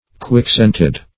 Quick-scented \Quick"-scent`ed\, a. Acute of smell.